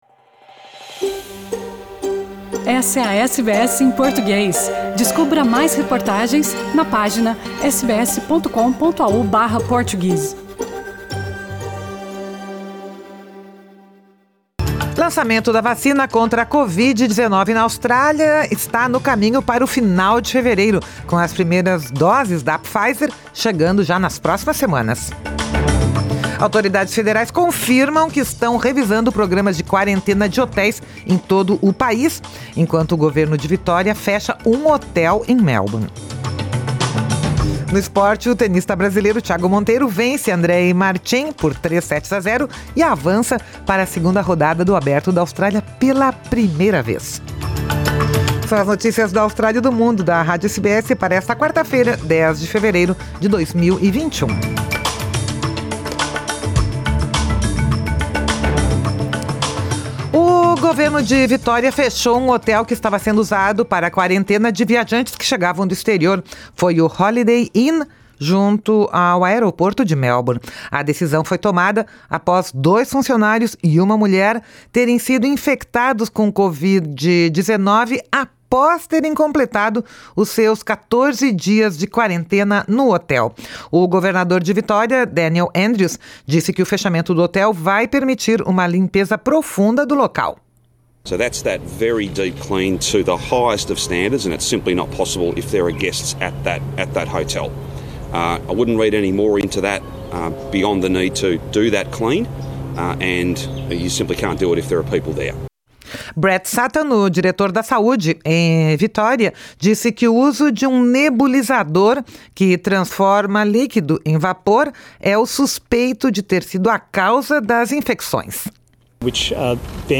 Notícias da Austrália e do Mundo | SBS em Português | 7 fevereiro 2021